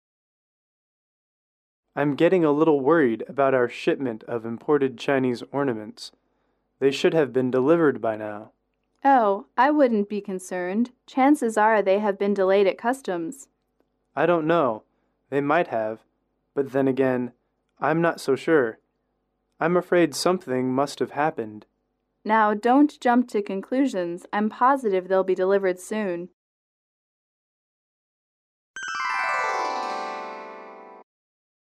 英语口语情景短对话15-2：货物延迟(MP3)